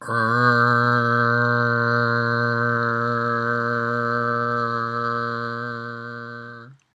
L and R sounds alone
r-only.mp3